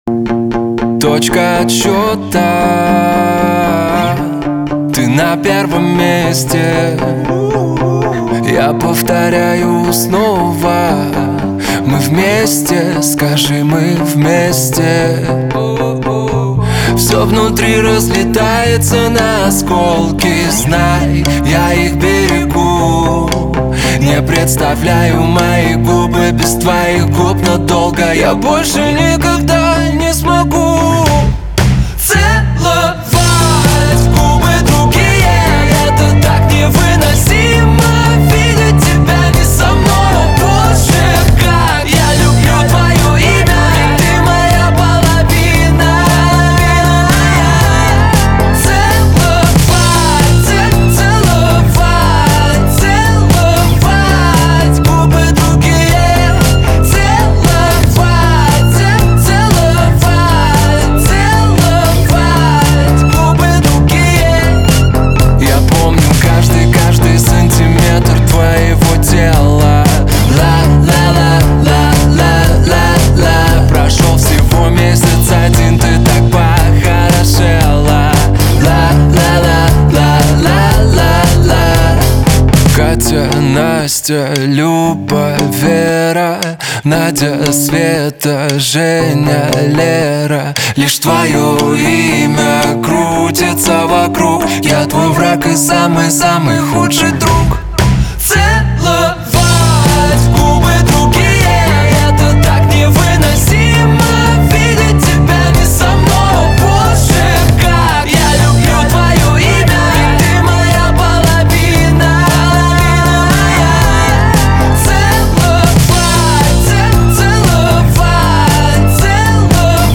диско , pop
эстрада